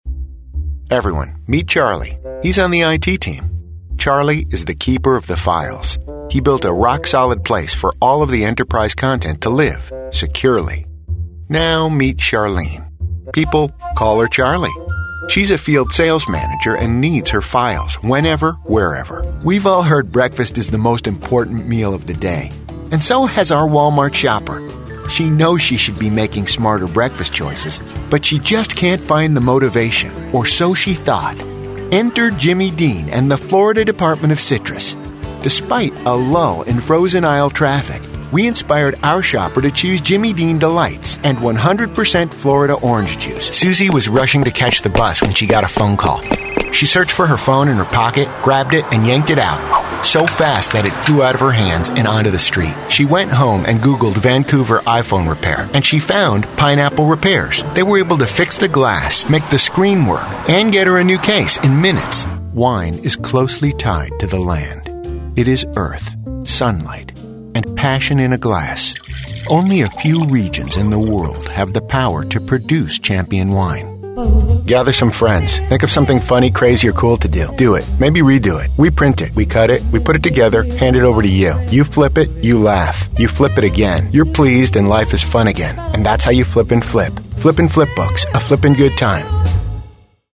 Male
English (North American), English (Neutral - Mid Trans Atlantic)
Adult (30-50), Older Sound (50+)
Real person, conversational. Not a typical announcer; a playful, engaging storyteller. A regular guy; the guy next door that people believe and trust.
Narration